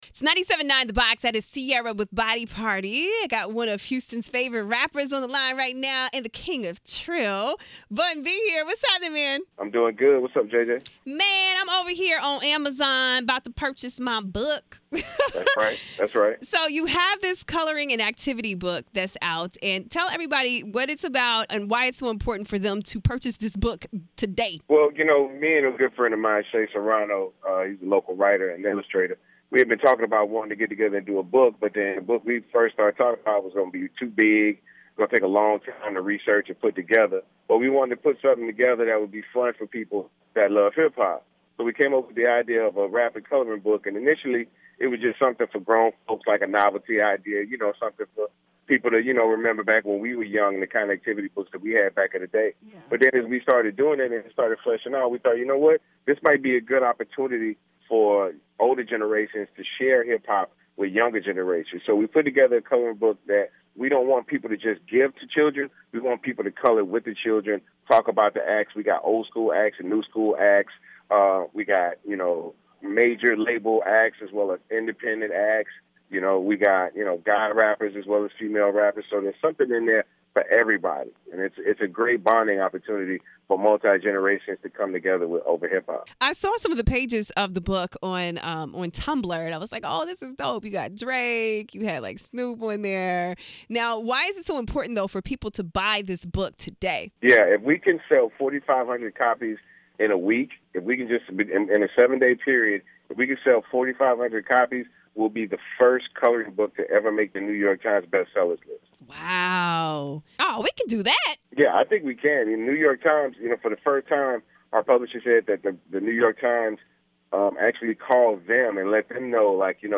Exclusive Interviews